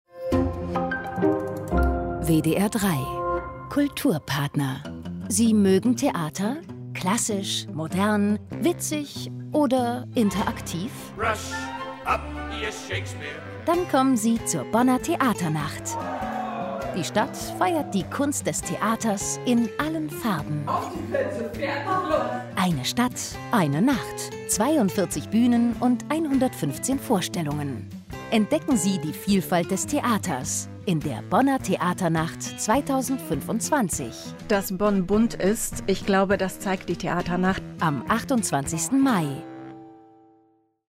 WDR 3 Kulturradio Spot zur Bonner Theaternacht 2025 - Bonner Theaternacht